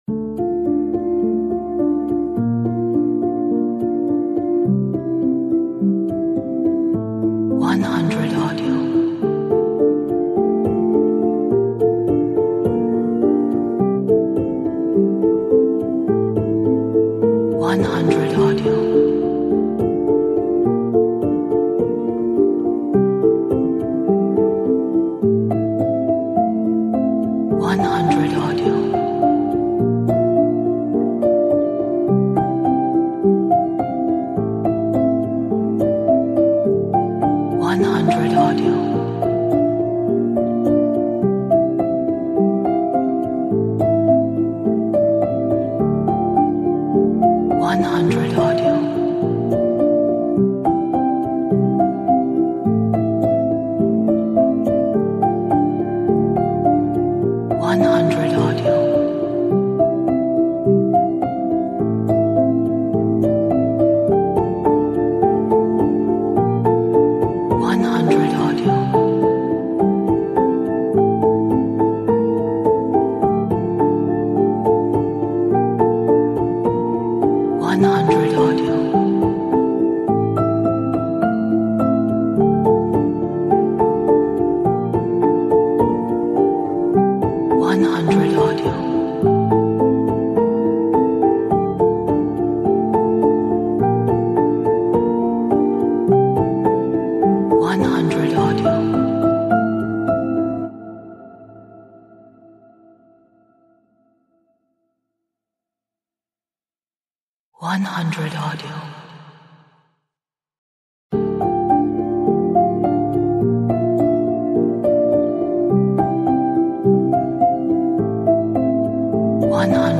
Atmospheric, dramatic, epic, energetic,